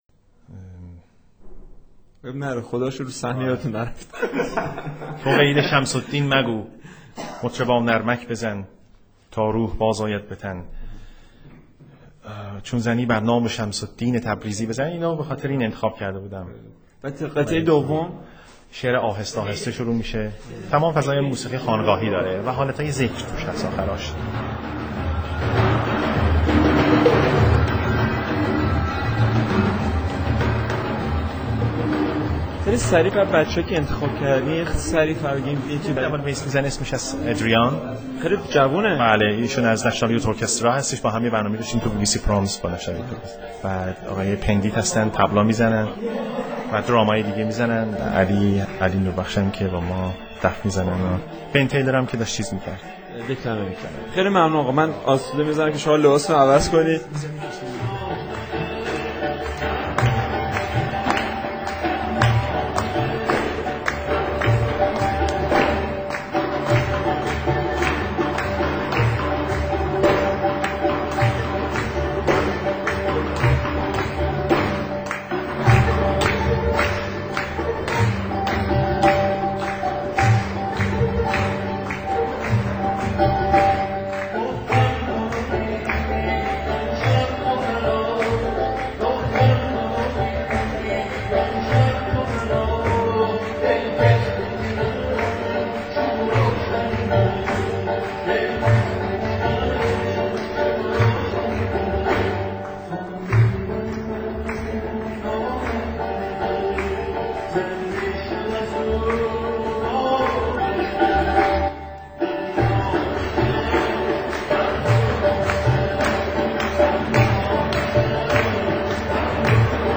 صدای گزارش از شب کنسرت - قسمت اول